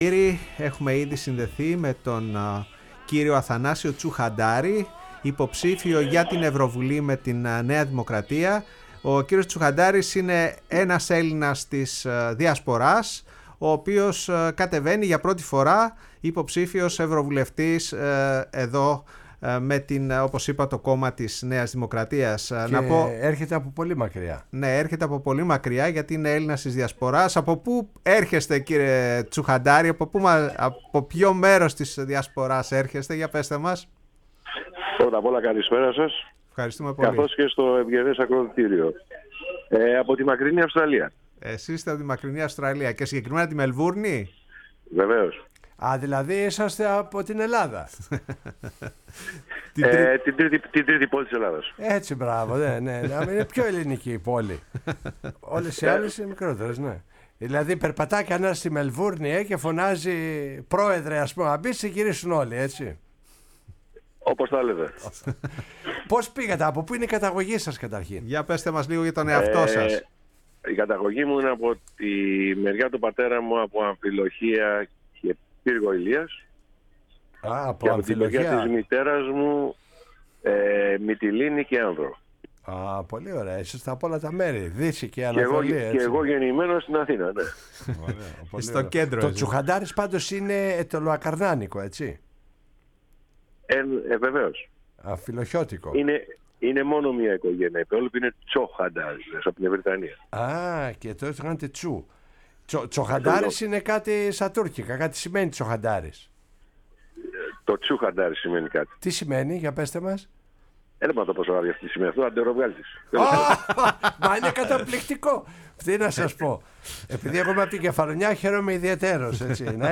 στο ραδιόφωνο της Φωνής της Ελλάδας και στην εκπομπή “Η Παγκόσμια Φωνή μας”